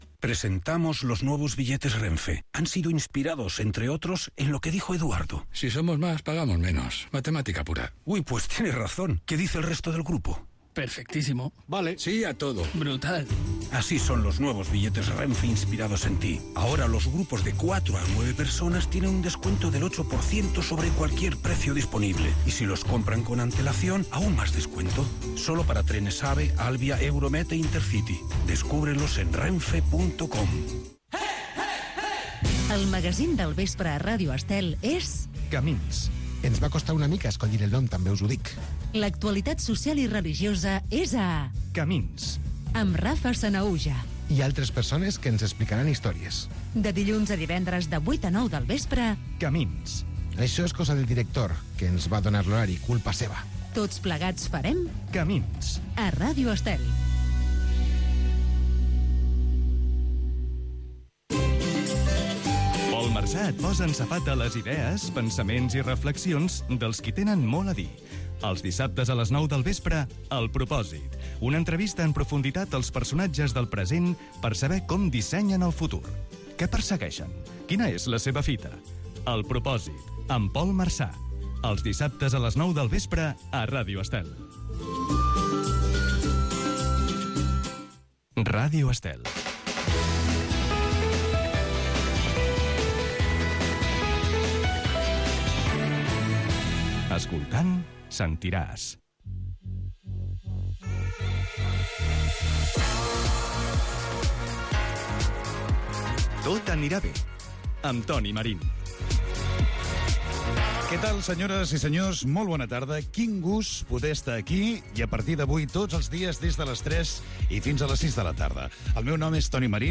fa81e70f5a6e6aeb465bc3a6b7fd78230dea98ef.mp3 Títol Ràdio Estel Emissora Ràdio Estel Cadena Ràdio Estel Titularitat Privada nacional Nom programa Tot anirà bé Descripció Publicitat, promocions dels programes "Camins" i "El propòsit", indicatiu de l'emissora. Presentació del primer programa amb el seu objectiu, hora, sumari del programa i col·laboradors.
Info-entreteniment